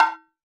Perc [ Water ].wav